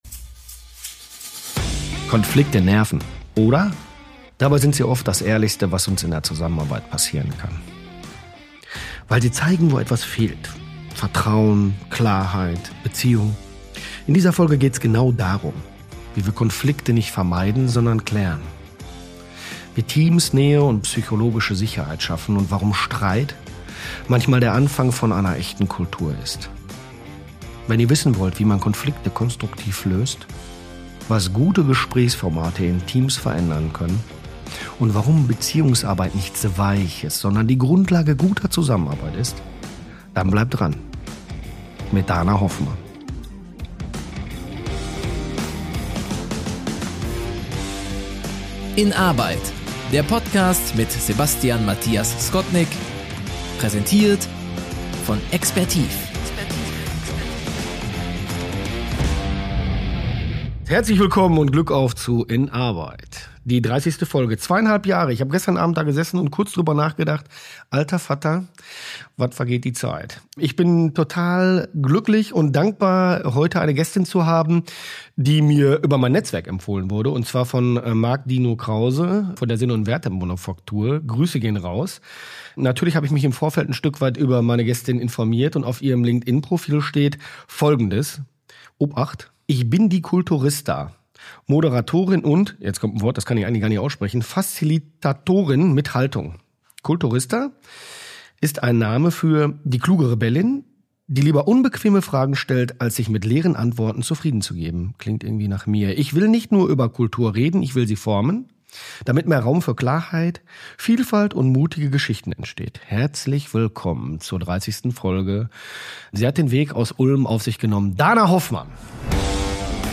Wie baut man psychologische Sicherheit in Teams auf, die noch nie gelernt haben, echte Konflikte zu führen? Und warum ist Streit manchmal der ehrlichste Impuls zur Veränderung? Ein Gespräch über restaurative Circles und Check-ins, über Rollenklarheit jenseits von Stellenbeschreibungen und die Kunst, in jeder Situation präzise die richtige Haltung einzunehmen – ohne sich selbst zu verlieren.